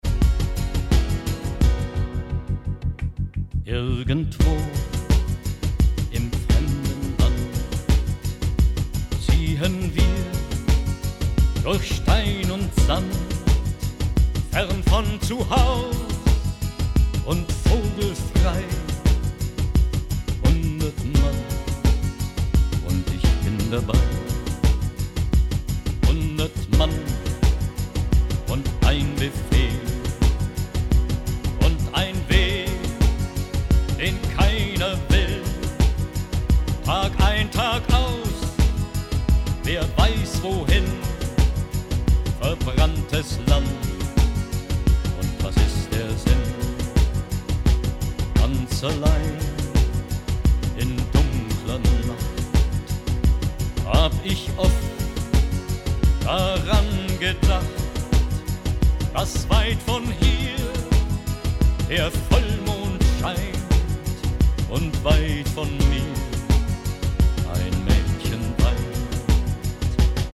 Disco-Version